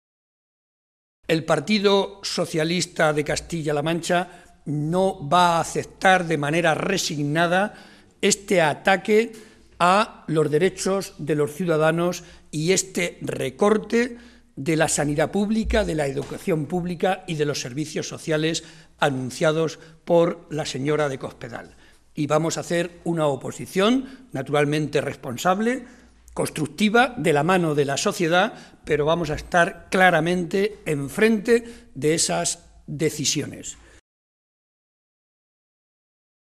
José María Barreda, secretario General del PSOE de Castilla-La Mancha
Cortes de audio de la rueda de prensa